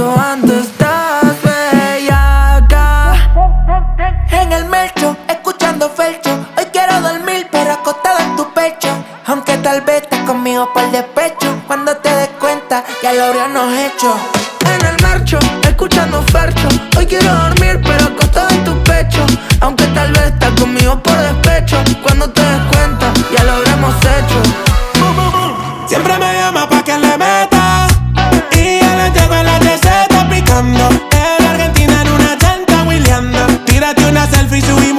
Жанр: Латиноамериканская музыка / Рэп и хип-хоп
# Latin Rap